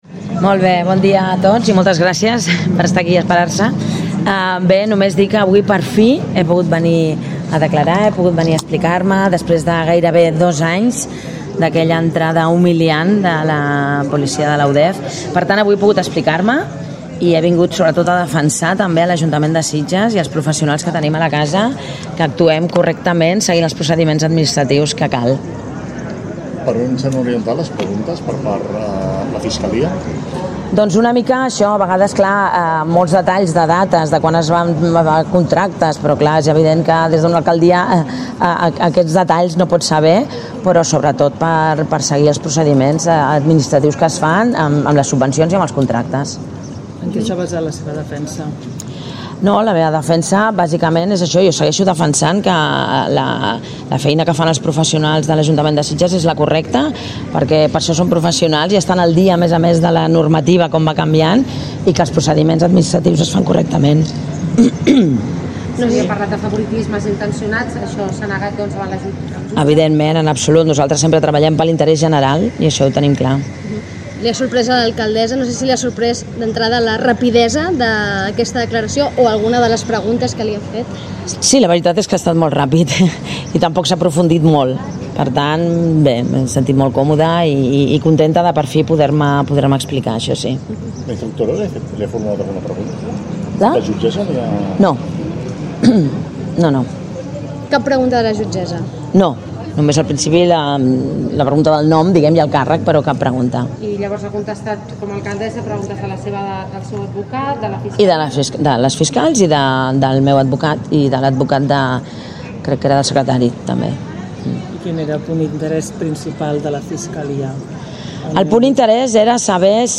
L’alcaldessa ha manifestat el desig que aviat es pugui arxivar el cas i ha demanat que es pugui rescabalar tant la seva imatge com alcaldessa com la de l’Ajuntament i dels seus treballadors després de gairebé dos anys d’aquella jornada de detencions del passat 21 de juny de 2023. Aurora Carbonell ha arribat als jutjats acompanyada dels companys regidors al govern d’ERC i també de la regidora de Sitges Grup Independent, Cristina Guiu i de Carme Gasulla dels Verds en Comú Podem i de molts altres membres i companys d’ERC a nivell local i territorial. Escolteu-la